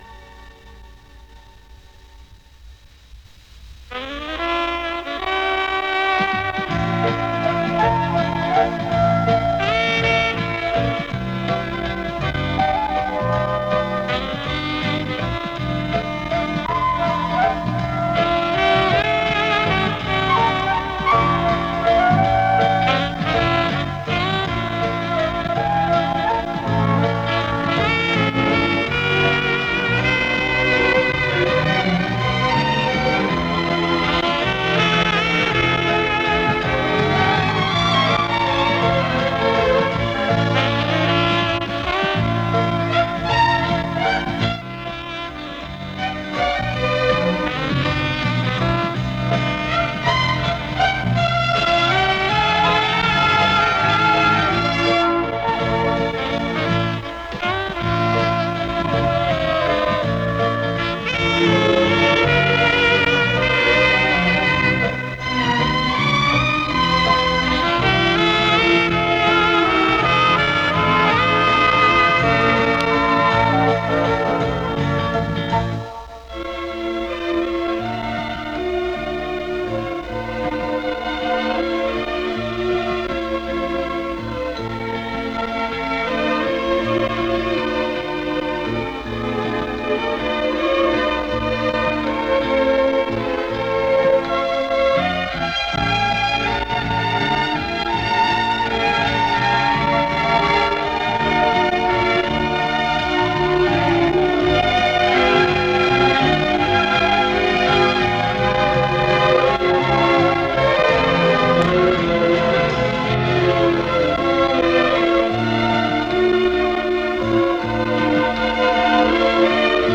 Помогите, пожалуйста, определить оркестры и исполняемые пьесы.